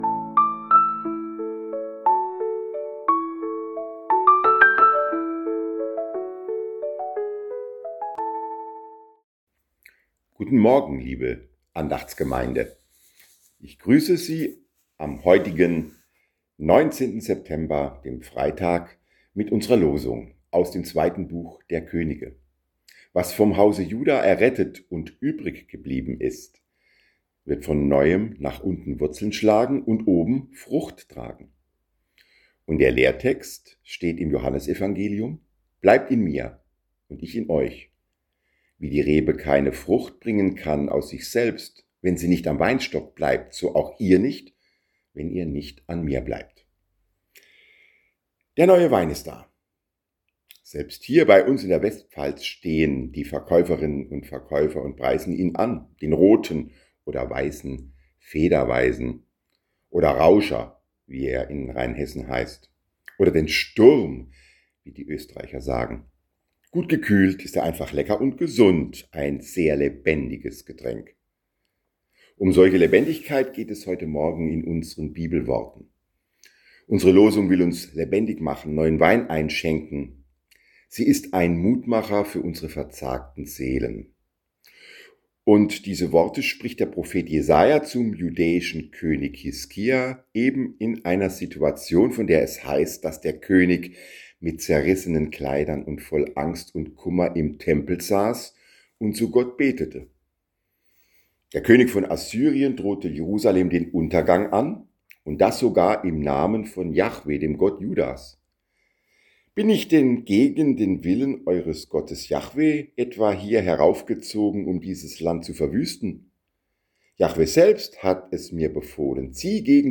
Man kann die Andachten auch abonnieren über: